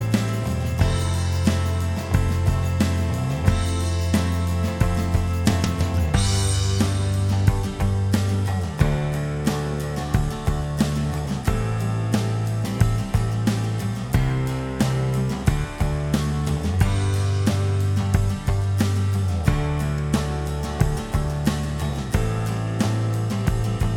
Minus Lead And Solo Indie / Alternative 4:25 Buy £1.50